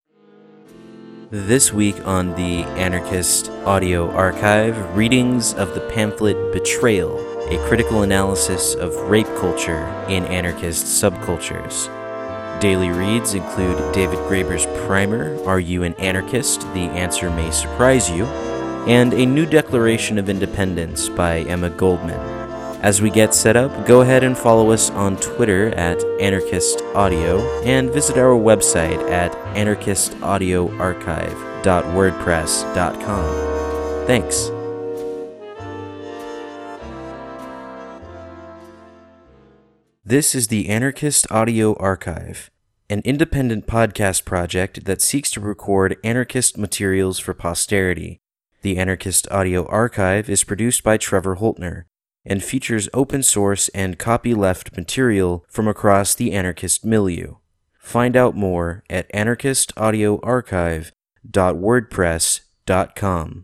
The Anarchist Audio Archive is an independent podcast project that seeks to produce high quality recordings of anarchist texts for posterity.